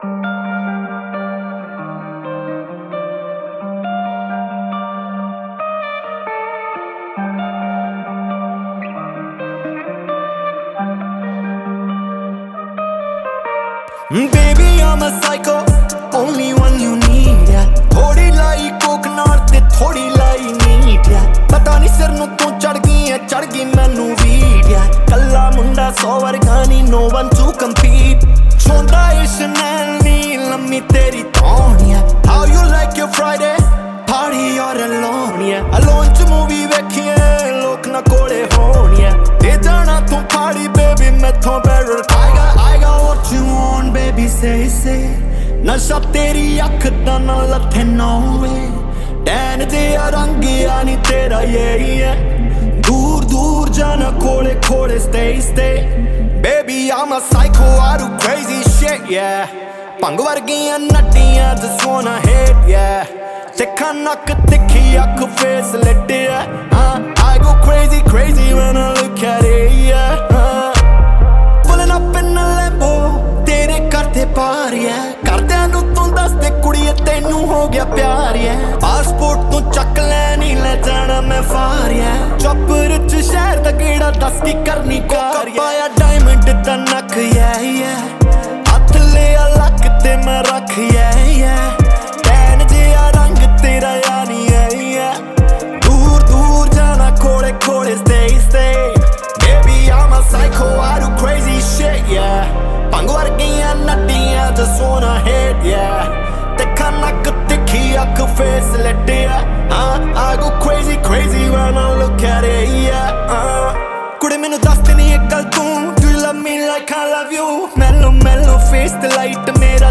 Punjabi Music